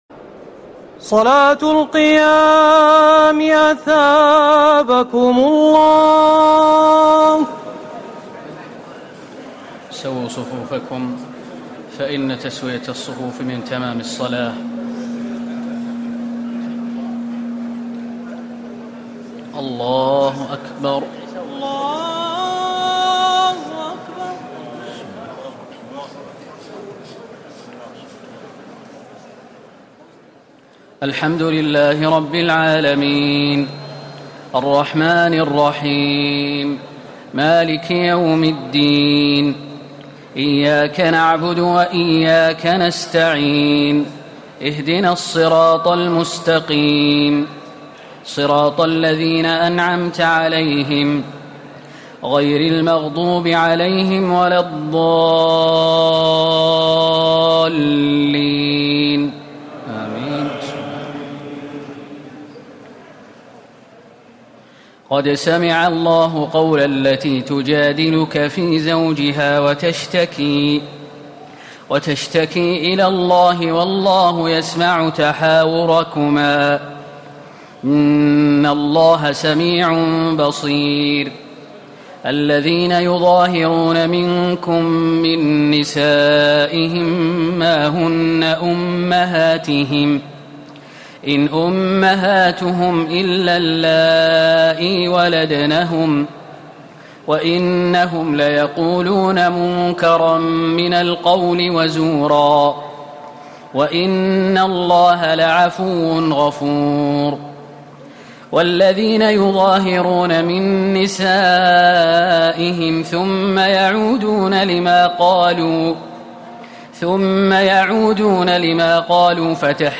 تراويح ليلة 27 رمضان 1439هـ من سور المجادلة و الحشر والممتحنة Taraweeh 27 st night Ramadan 1439H from Surah Al-Mujaadila and Al-Hashr and Al-Mumtahana > تراويح الحرم النبوي عام 1439 🕌 > التراويح - تلاوات الحرمين